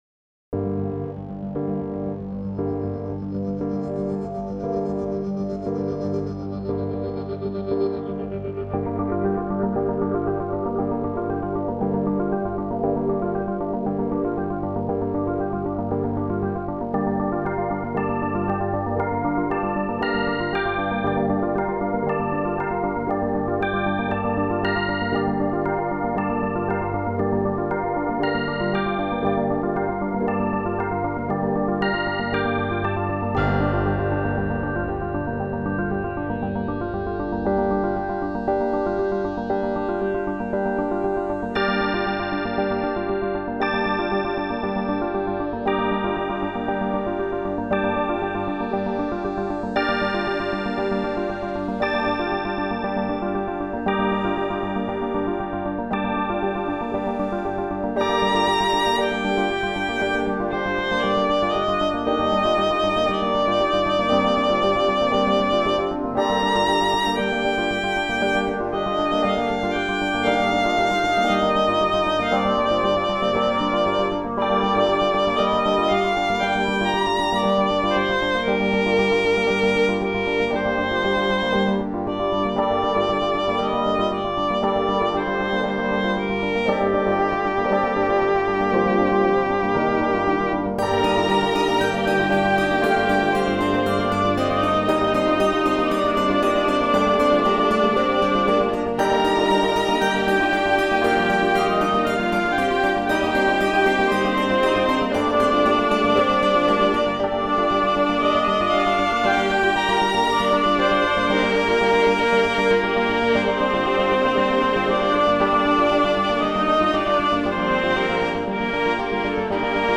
all electronic version from May 2022